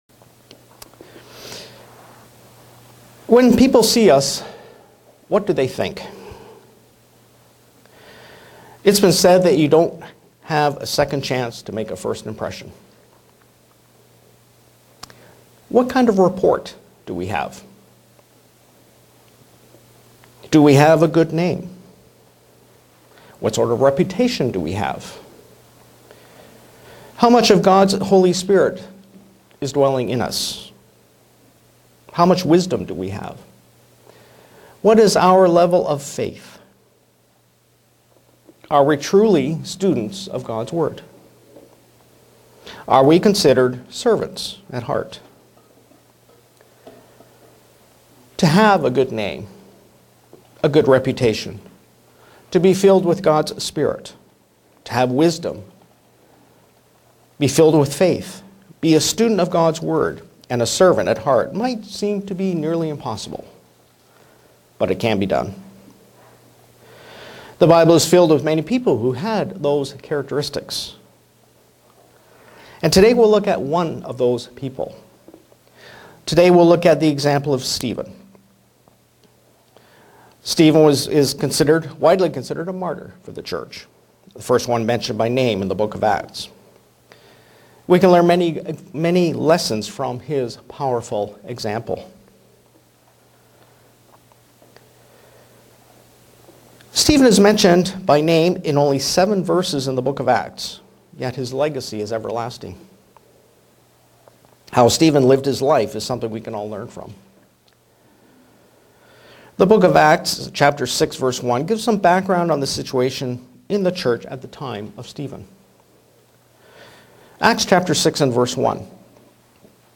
Print The lessons we can learn from Stephen's example and his strong faith in God. sermon Studying the bible?